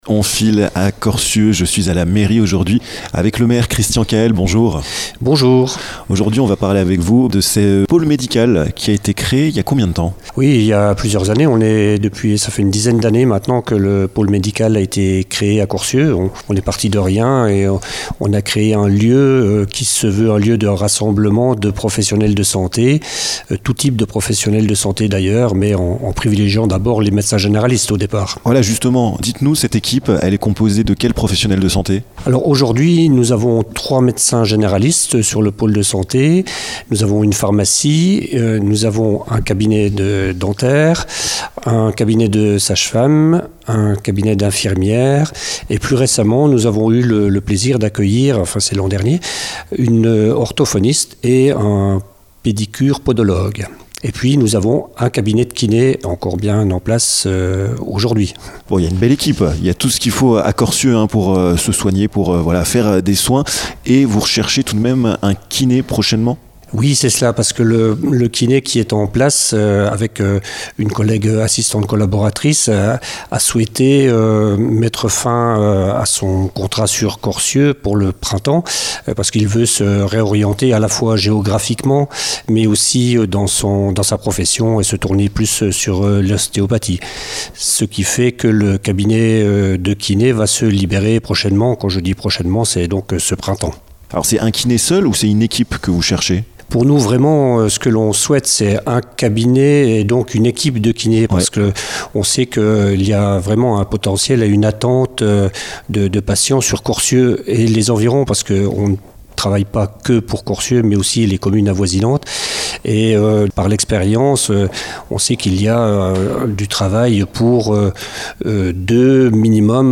Le maire de Corcieux, Christian Caël, nous à reçu en mairie. Il nous explique dans ce podcast la création du pôle médical, les professionnels de santé qui le composent et lance un appel pour que des kinés les rejoignent !